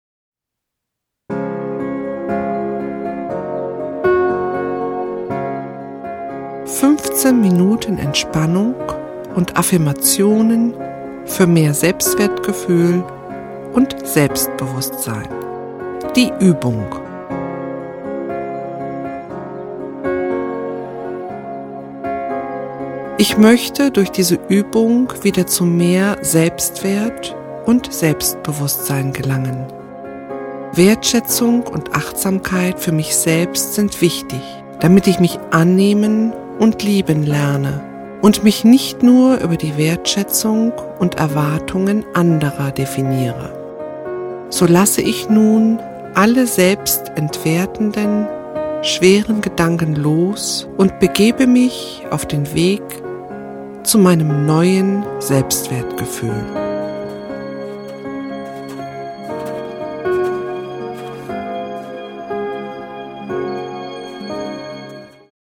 In der öfteren Anwendung werden sich diese Affirmationen setzen und durch die spezielle stimulierende Hintergrundmusik in Ihnen firmieren.
Dem einen fällt die Entspannung bei einer tieferen männlichen Version leichter, den anderen inspiriert die weibliche ruhige Stimmlage.
Männliche Stimme   15:15 min